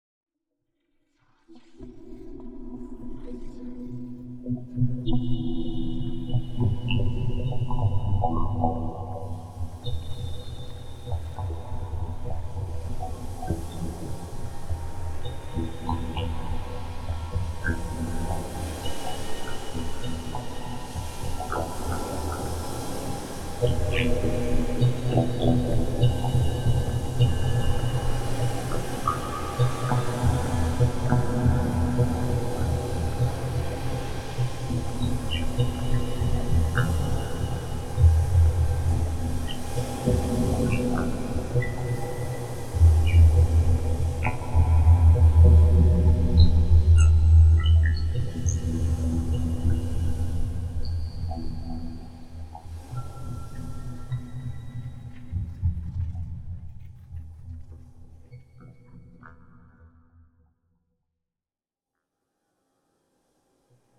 GMTK25 / world_assets / audio / ambiance / cave.aif
cave.aif